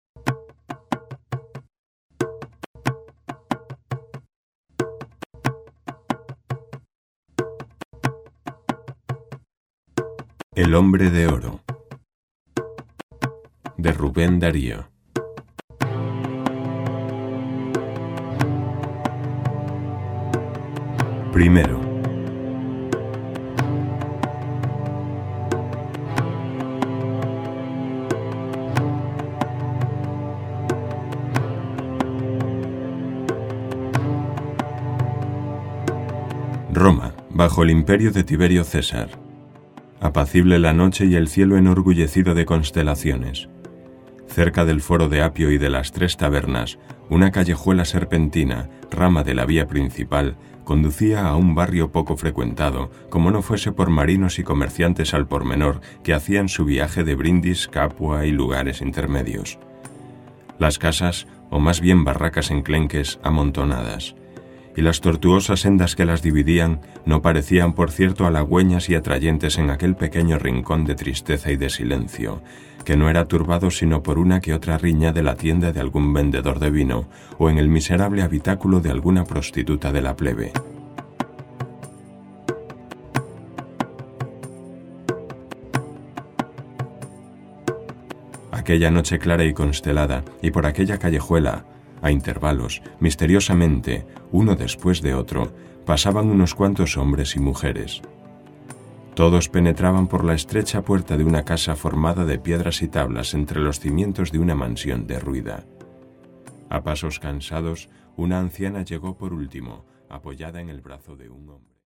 Música y ambientación:
Audiolibros con alma